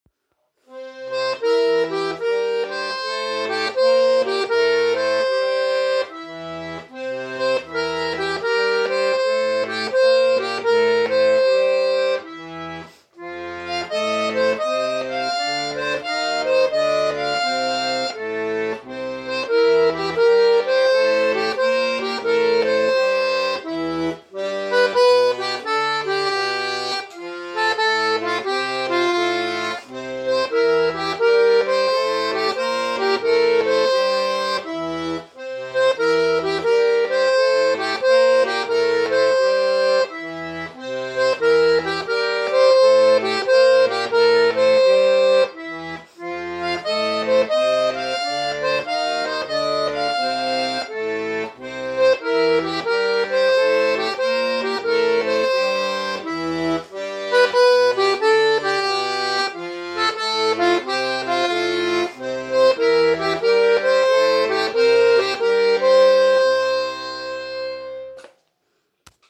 Ist es überhaupt Klezmer oder sonst was osteuropäisches?
Sobald die Aufnahme läuft, macht man die blödsten Fehler.